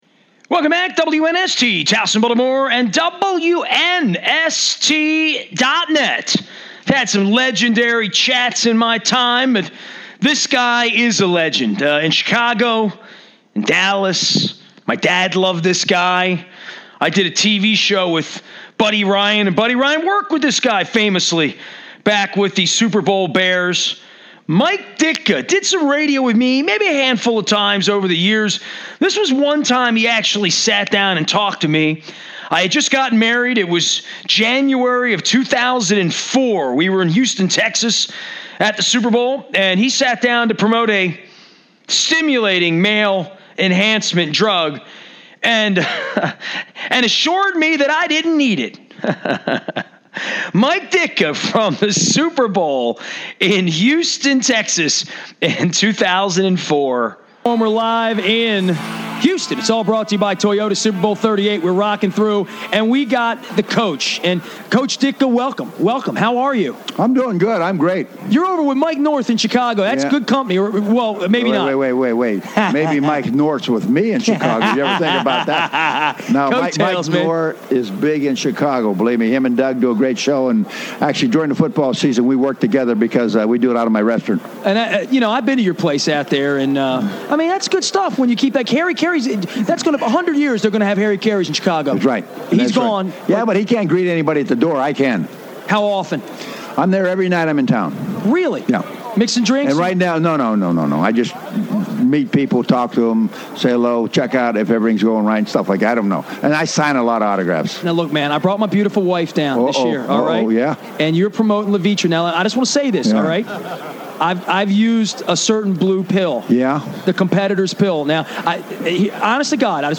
Mike Ditka Super Bowl Houston Radio Row 2004